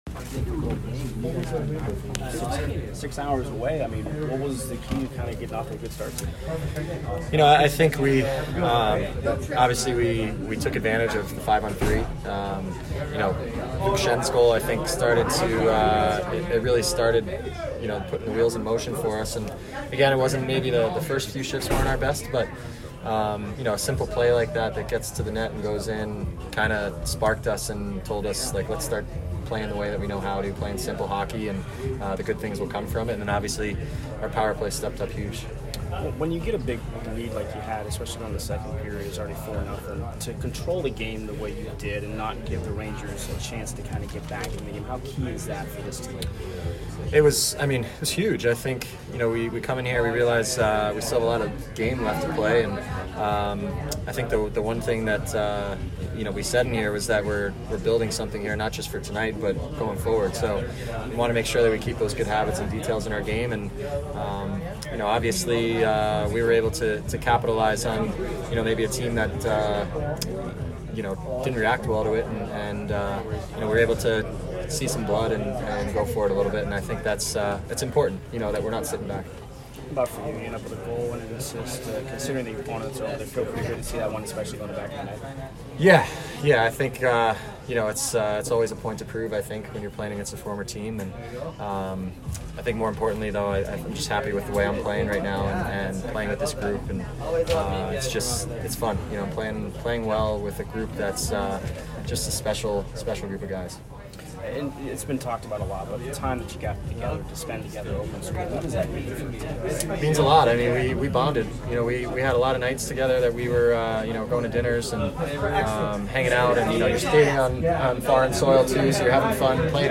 Kevin Shattenkirk post-game 11/14